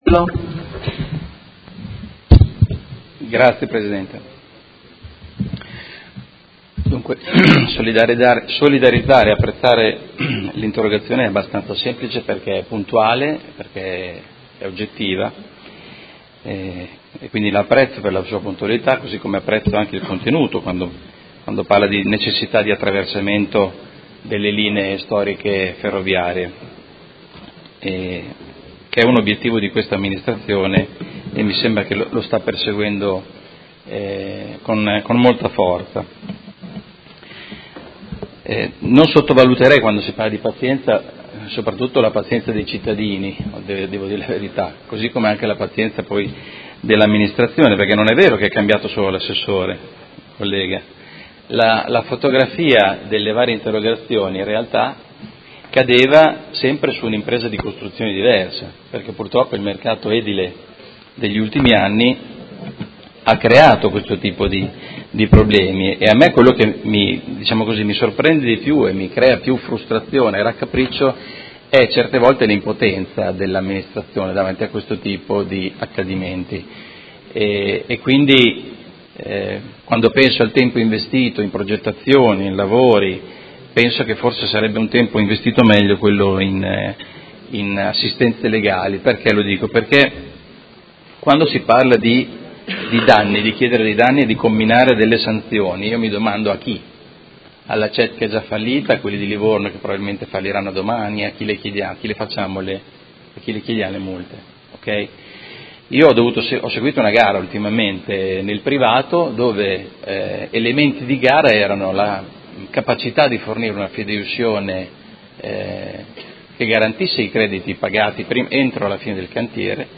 Seduta del 31/05/2018 Interrogazione del Gruppo M5S avente per oggetto: Completamento sottopasso ferroviario tra zona Crocetta (ex BenFra) e Via Scaglietti (ex Acciaierie) a fianco del Cavalcavia Ciro Menotti. Trasformata in interpellanza su richiesta del Consigliere Stella.